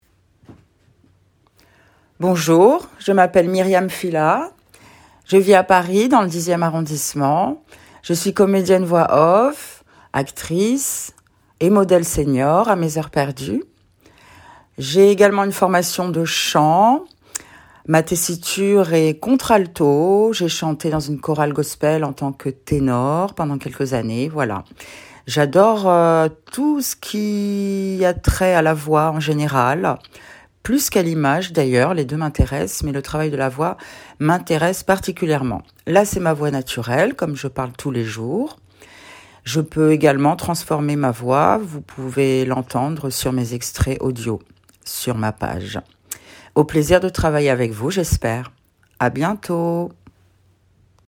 Voix off
Tessiture : Contre-alto.
30 - 50 ans - Ténor Contralto